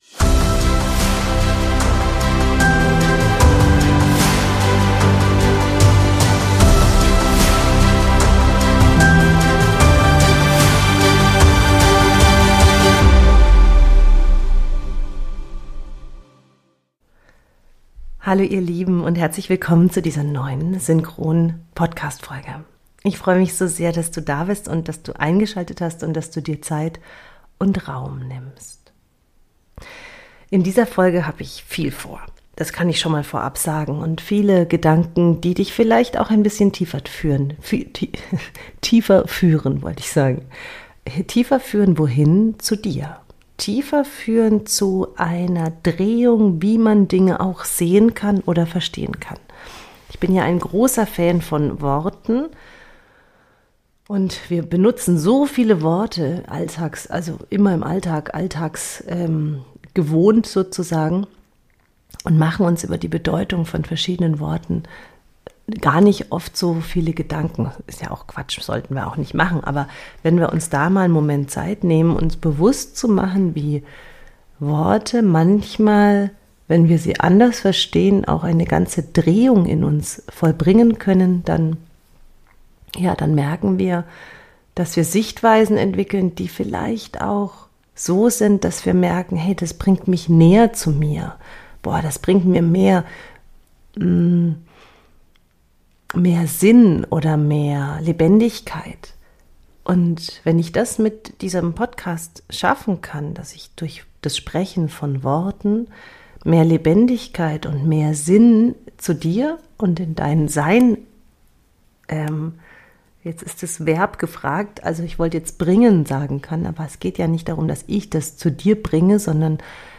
In dieser tiefen, lebendigen Solo-Folge nehme ich dich mit auf eine Reise durch Fragen, Perspektivwechsel und Erkenntnismomente.